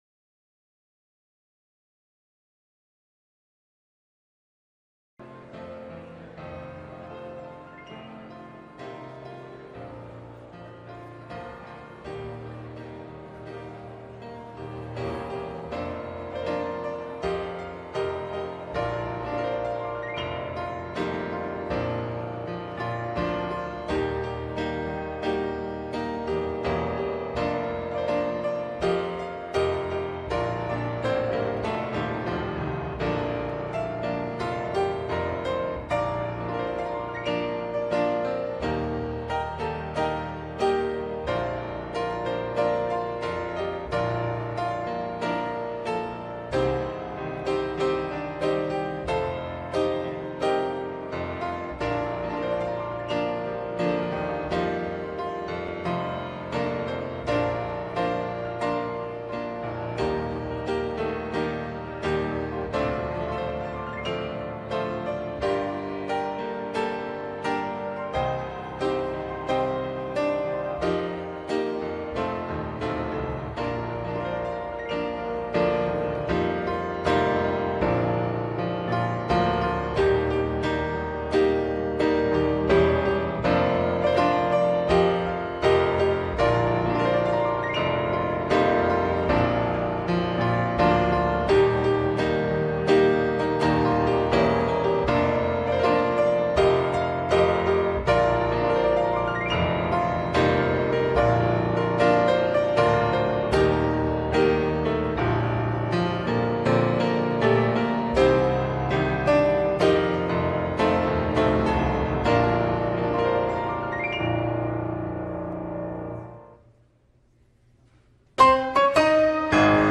Live Sunday Morning Service